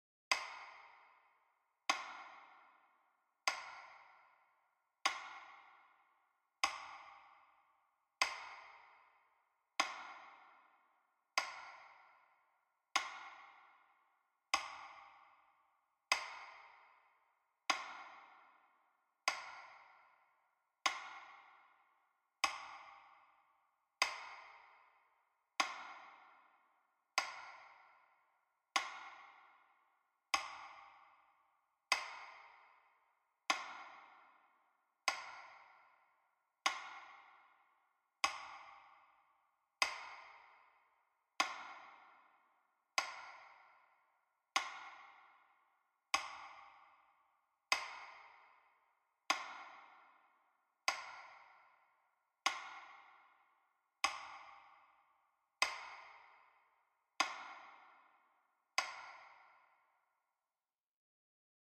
Тихий звук часов для минуты молчания